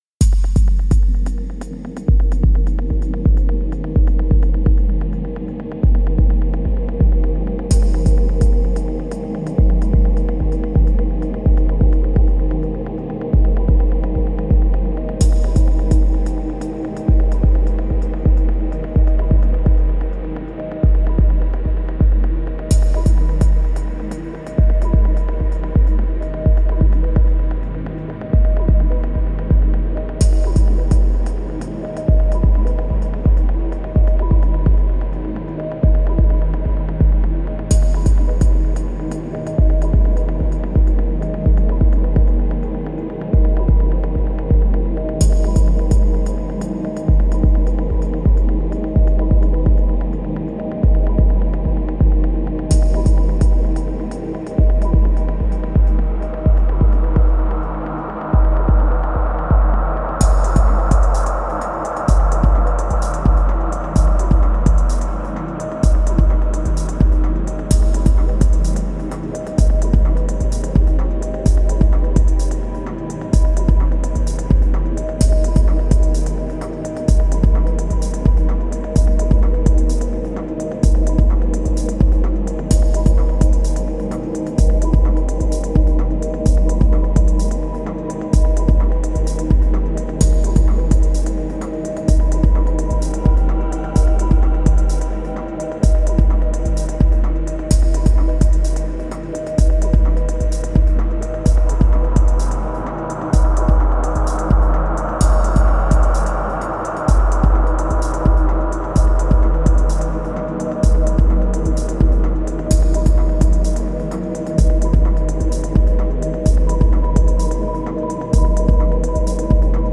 Genre: Ambient/Techno Ambient/Techno.